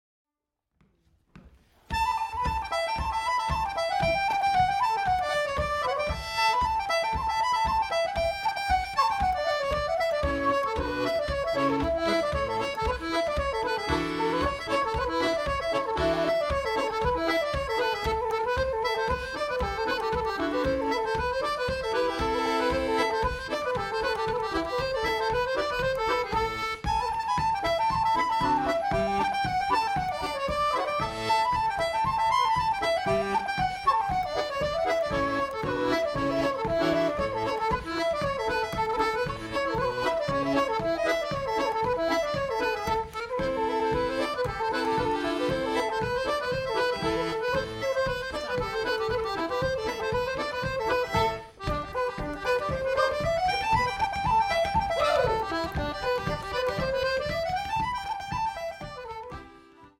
Reels 3.16